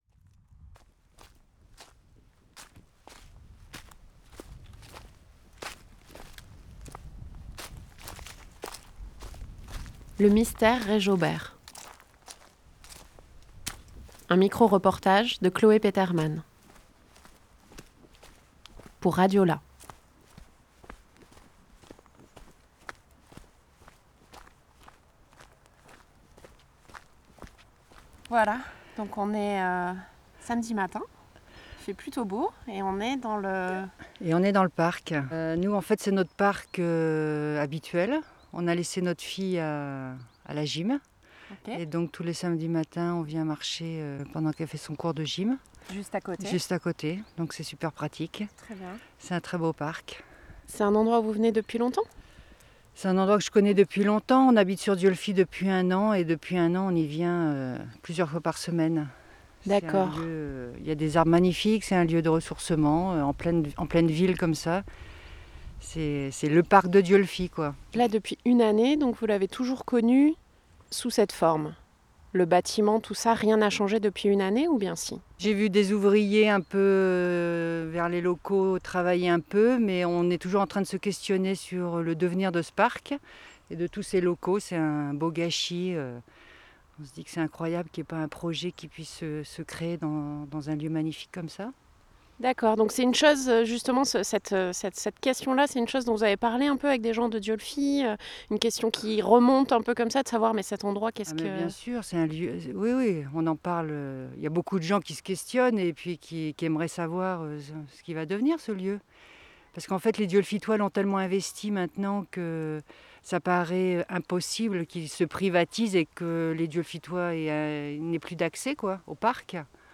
7 janvier 2020 16:35 | ateliers, Interview, reportage
Produit dans le cadre des formations radios proposées par RadioLà.